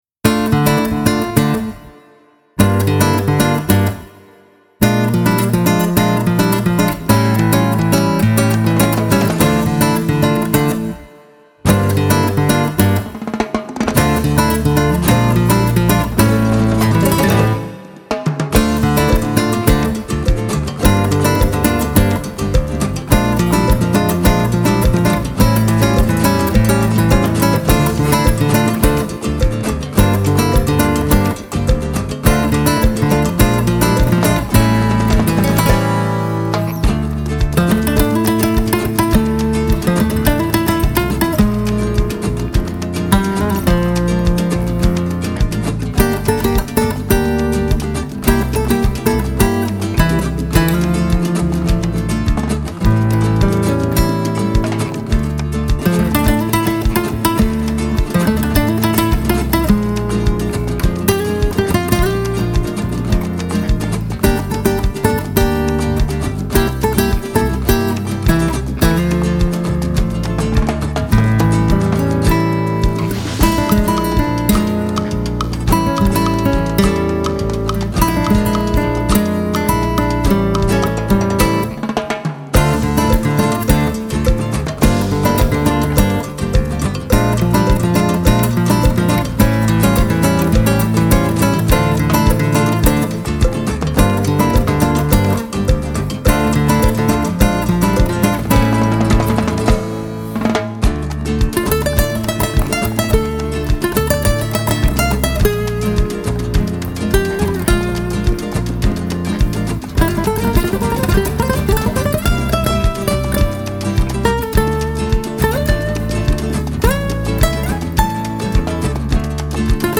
آرامش بخش , امید‌بخش , گیتار , موسیقی بی کلام
فلامنکو